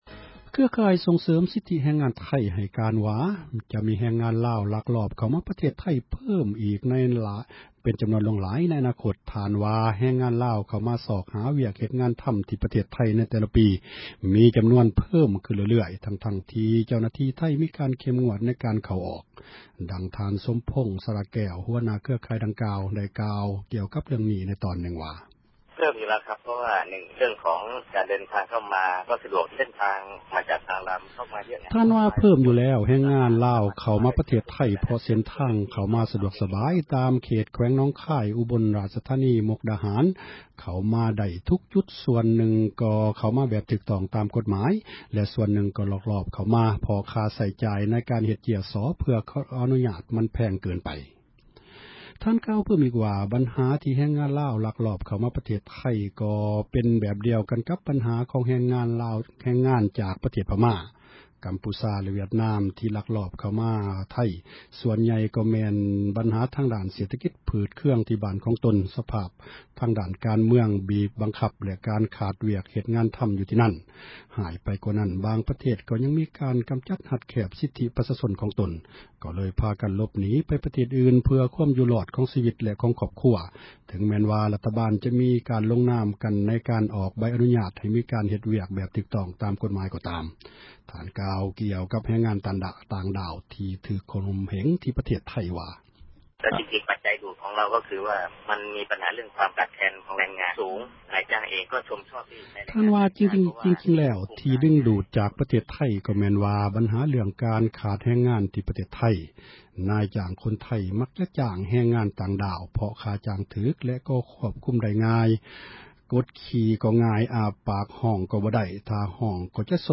ສຽງແຮງງານລາວໃນໄທ